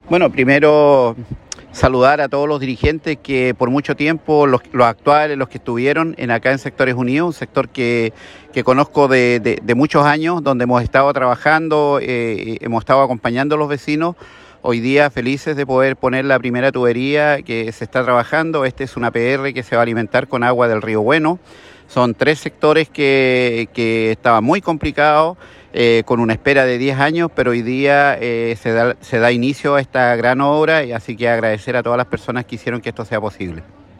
alcalde-reinoso-por-APR-sectores-unidos.mp3